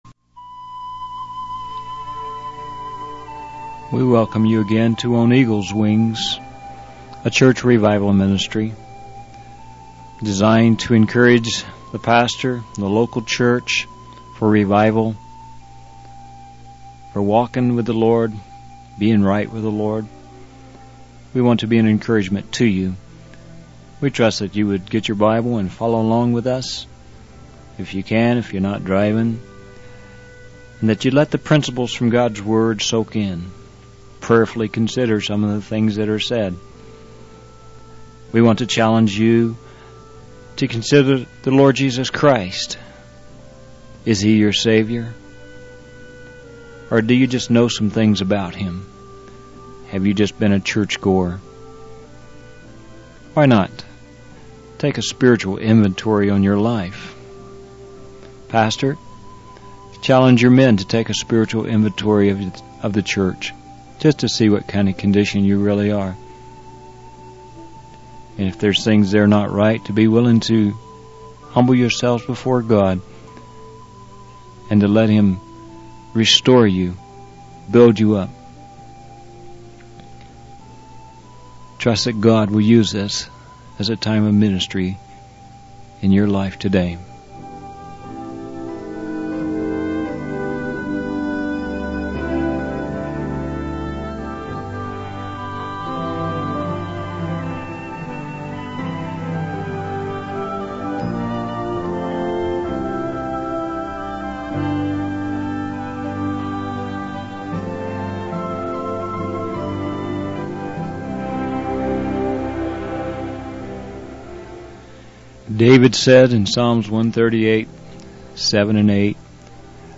In this sermon, the preacher emphasizes the importance of the Great Commission and its connection to Pentecost. He urges pastors, evangelists, and church leaders to call the church to repentance and to return to the significance of Calvary and Pentecost. The preacher then shifts to discussing the life of Moses, highlighting his close relationship with God and the lessons he learned in the desert.